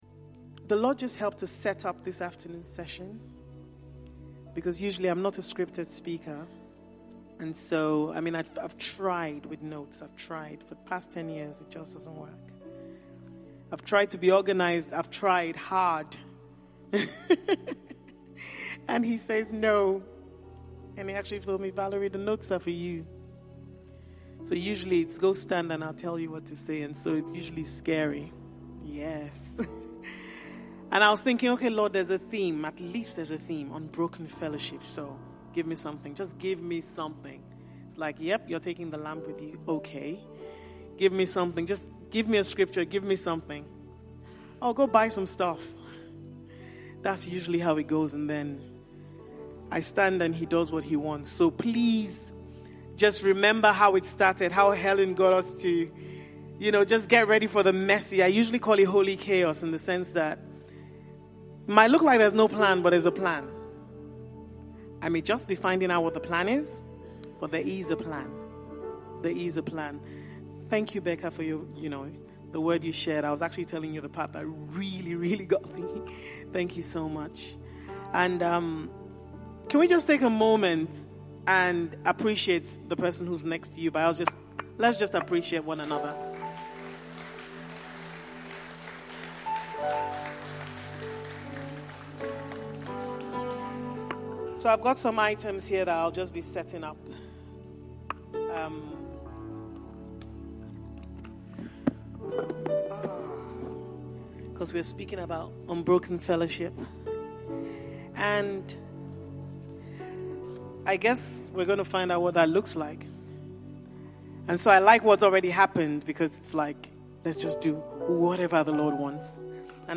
Ladies Conference